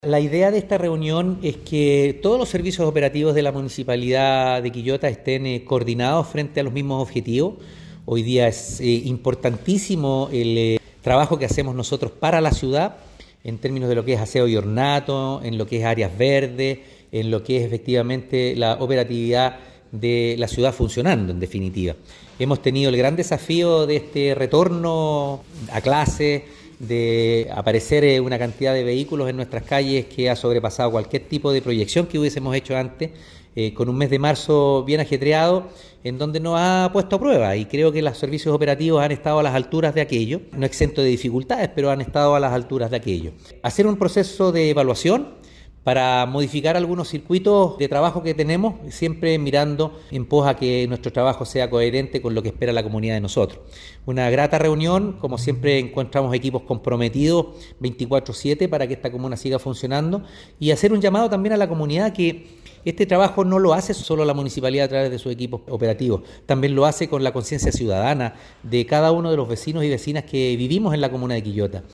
Alcalde-Oscar-Calderon-Sanchez-3.mp3